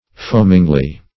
foamingly - definition of foamingly - synonyms, pronunciation, spelling from Free Dictionary Search Result for " foamingly" : The Collaborative International Dictionary of English v.0.48: foamingly \foam"ing*ly\, adv.